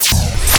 bullet.wav